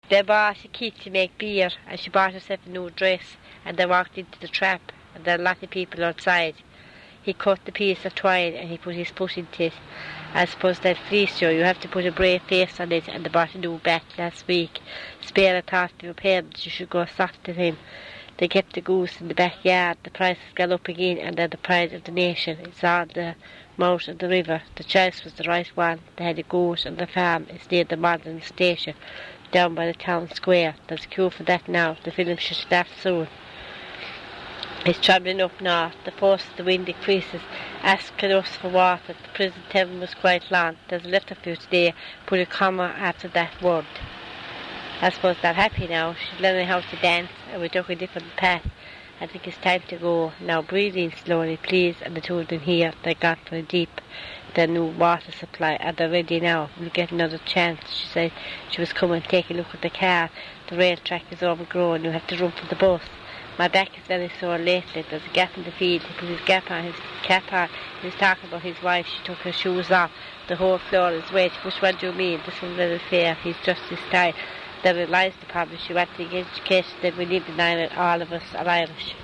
Elderly speaker from Limerick (mid west)
LIM_Rathkeale_R1_F_70.wav